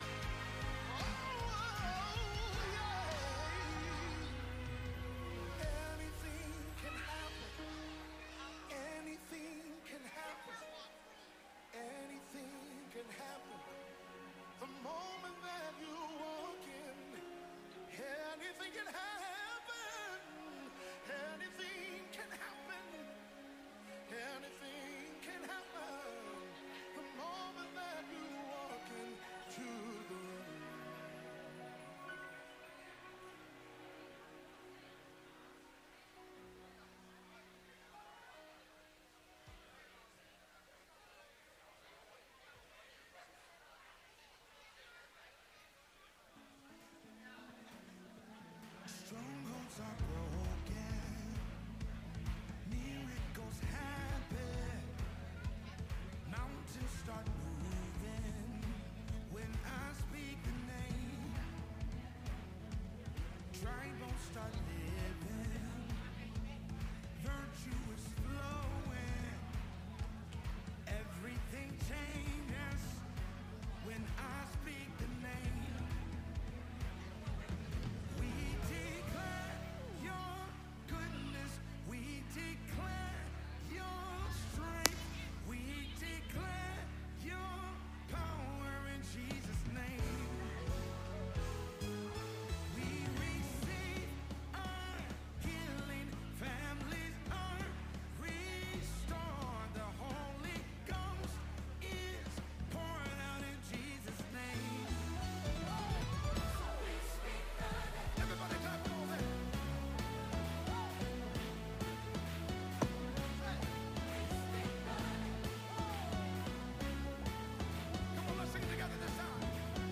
Grace Church Wednesday Service. The Truth About The Rapture.